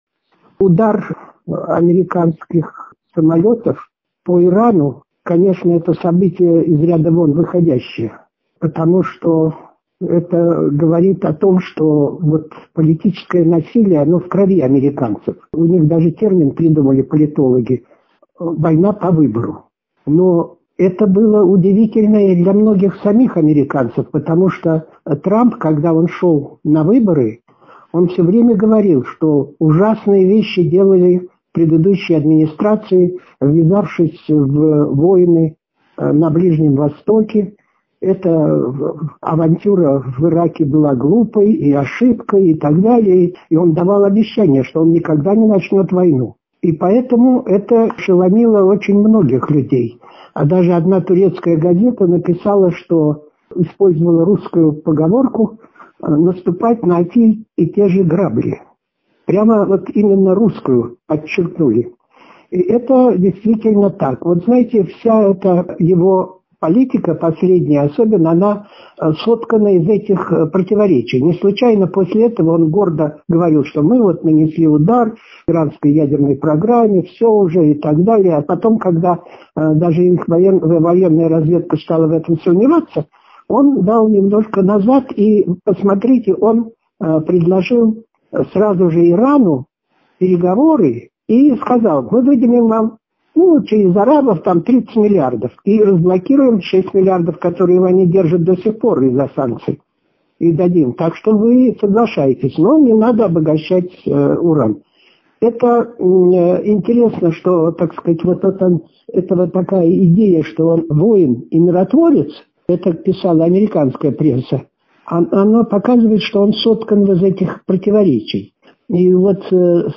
Чрезвычайный и полномочный посол, директор Центра партнерства цивилизаций Института международных исследований МГИМО(У) МИД России Вениамин Попов в интервью журналу «Международная жизнь» рассказал о конфликте Ирана с США и Израилем: